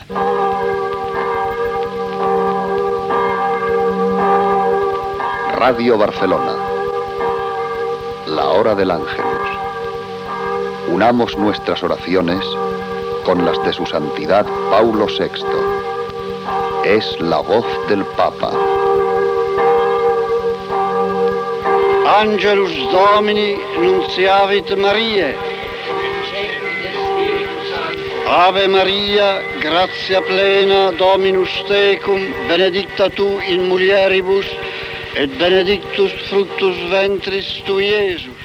Campanes, identificació de l'emissora i pas a l'oració de l'Àngelus recitada en llatí pel Sant Pare Pau VI.
Religió